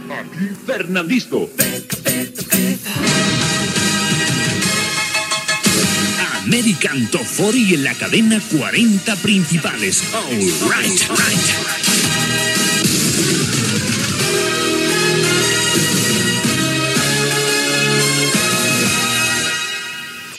Indicatiu del programa.